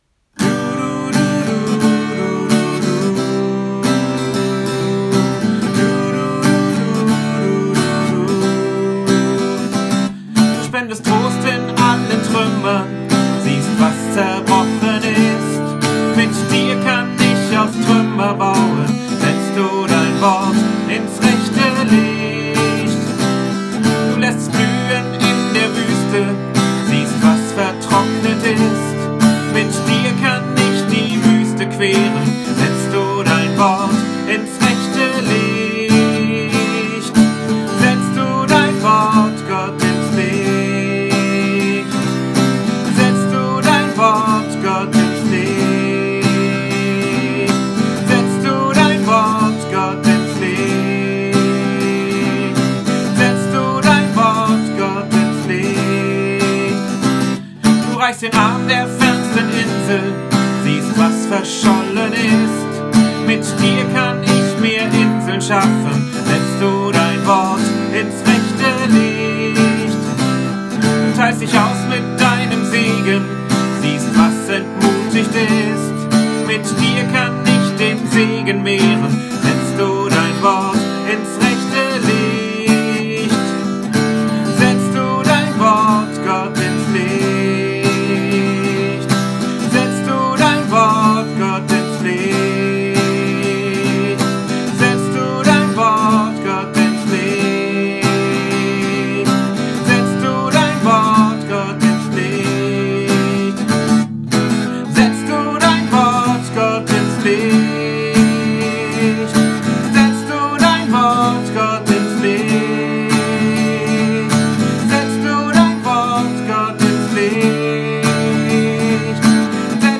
Musik: "Du spendest Trost!"